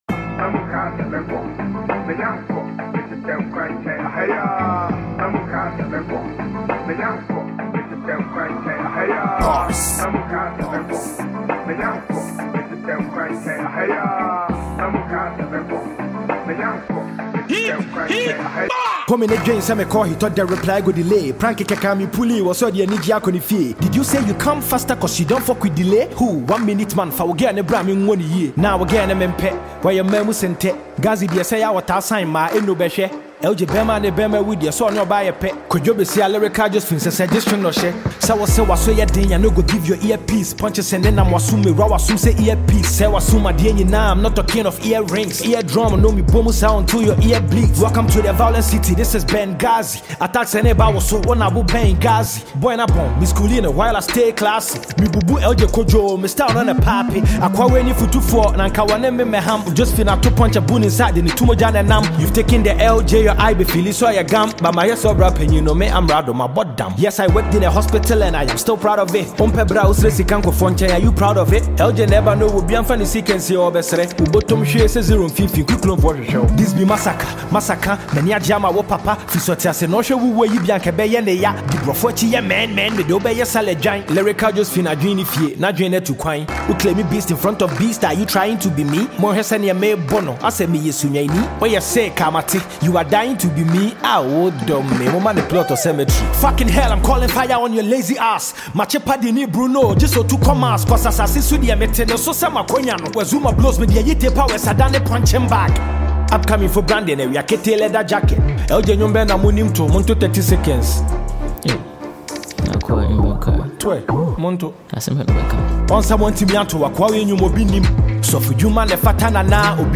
diss song